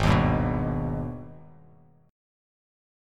AbmM7 chord